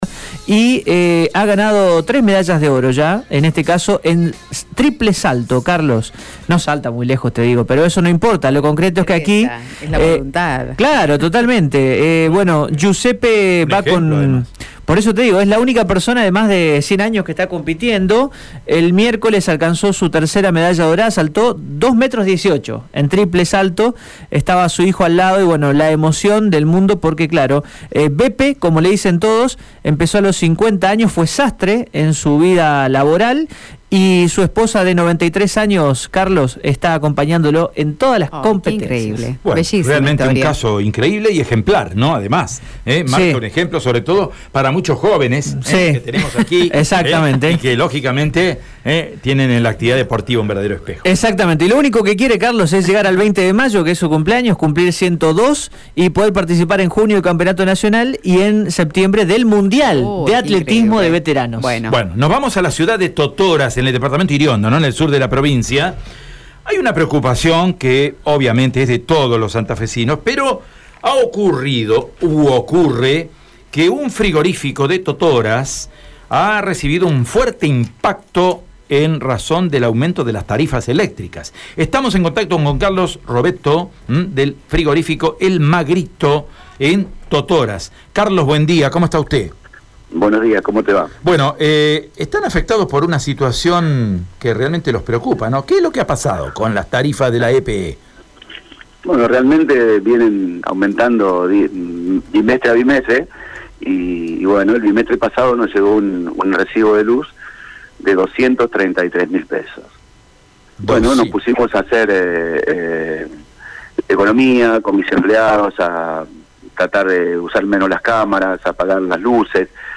“No queremos cerrar, pero la última factura no la voy a pagar hasta que no me den una solución”, indicó indignado.